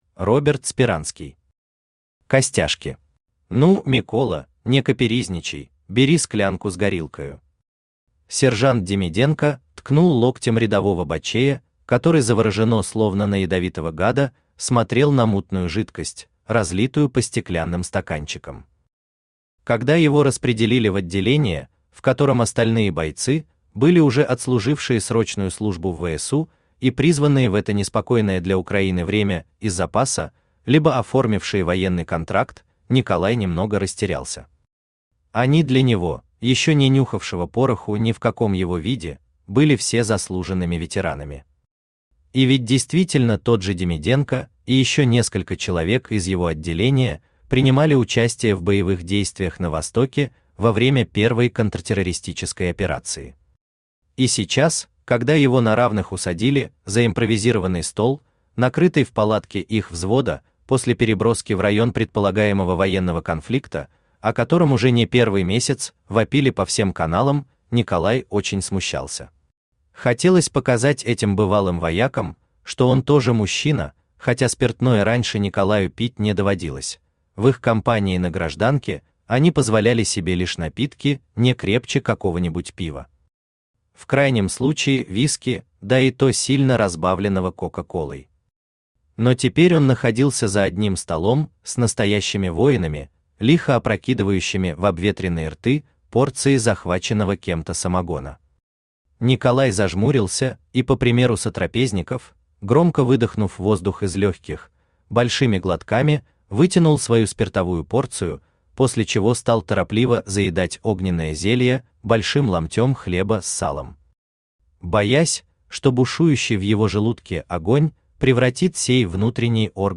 Аудиокнига Костяшки | Библиотека аудиокниг
Aудиокнига Костяшки Автор Роберт Юрьевич Сперанский Читает аудиокнигу Авточтец ЛитРес.